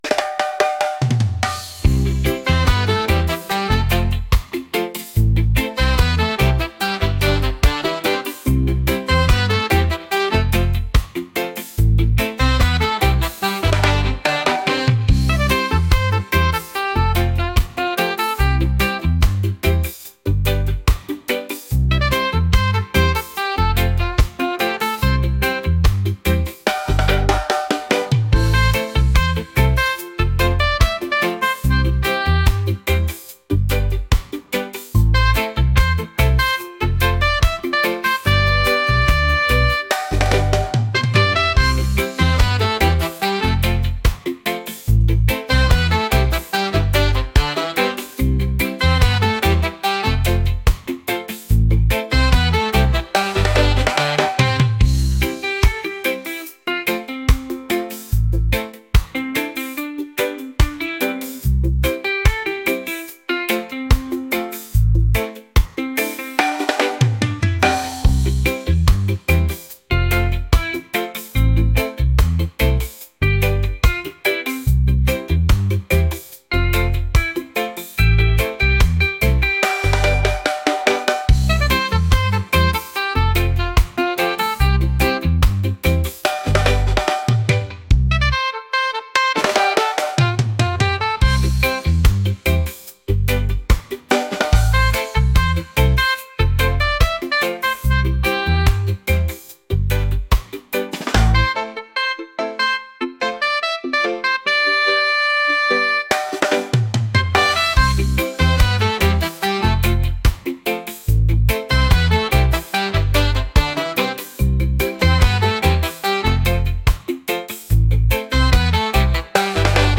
reggae | upbeat | lively